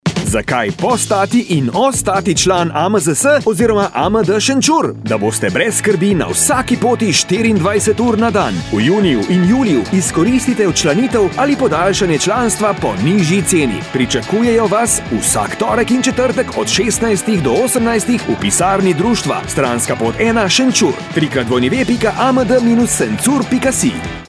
Radijski spot AMD Šenčur
Radijski spot so pripravili in ga vrtijo na Radiu Gorenc.